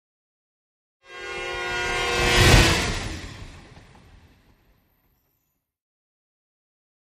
Train Whistle By European 1 - L to R